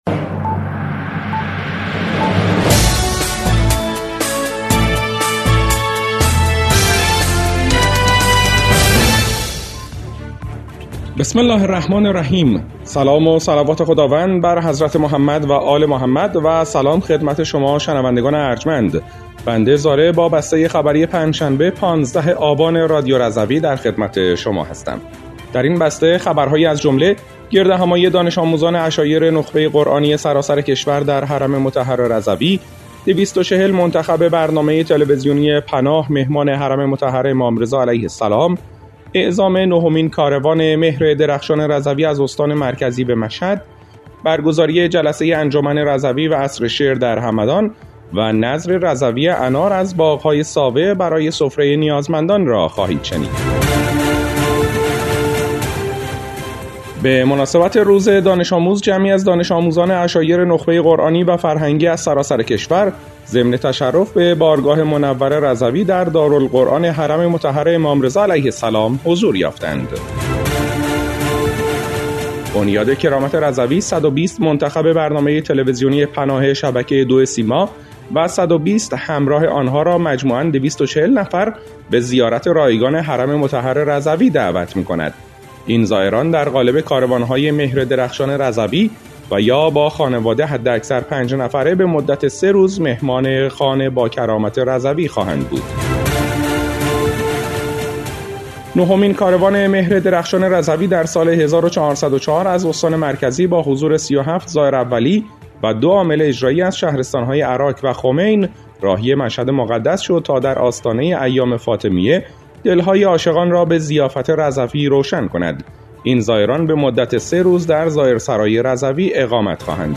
بسته خبری ۱۵ آبان ۱۴۰۴ رادیو رضوی؛